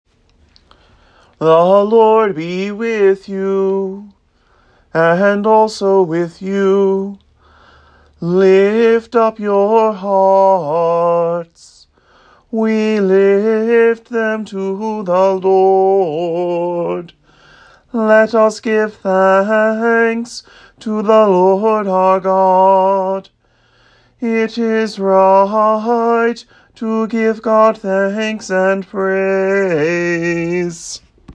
Below you will find a draft bulletin with audio files to listen and practice singing along as St. Hilda St. Patrick observes a principal feast with considerably more chanting.